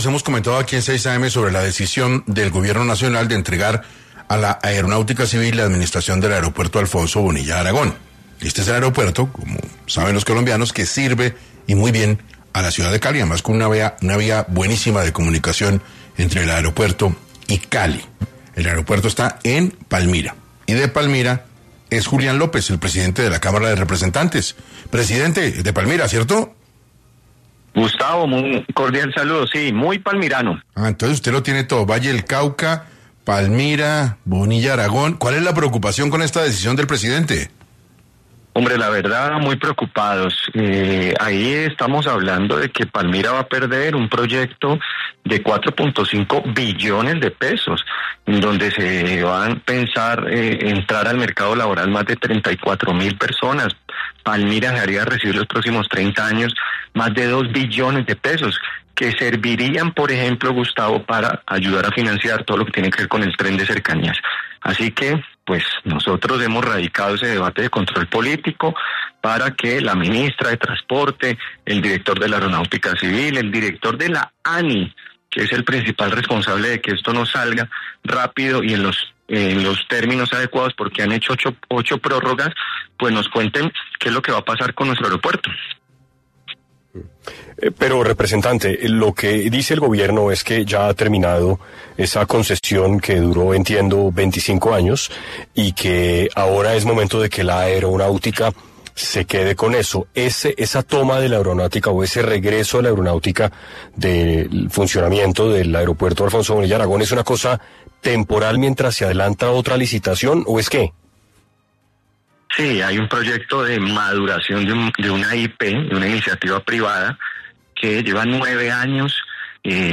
Julián López, presidente de la Cámara de Representantes, estuvo en 6AM para hablar de la operación y administración del aeropuerto Internacional Alfonso Bonilla Aragón de Palmira, que asumió la Aerocivil.